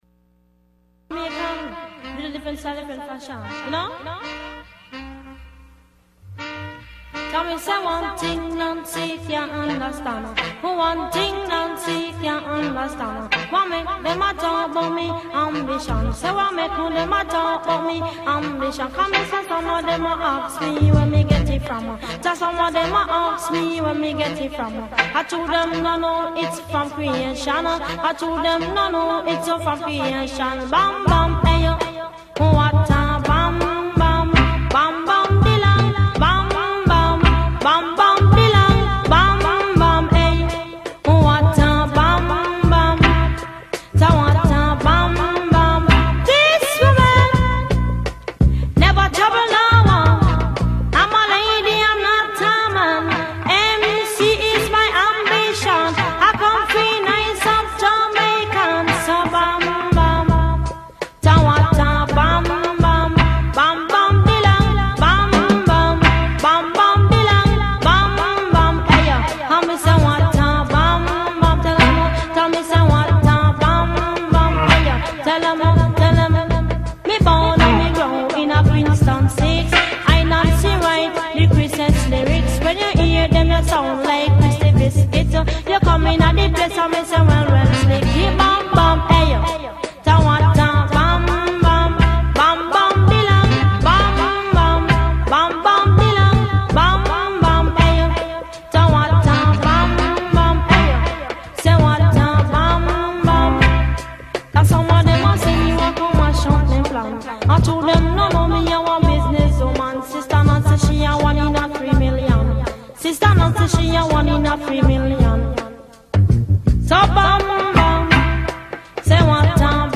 Youth Radio Raw is a weekly radio show produced by Bay Area youth ages 14-18.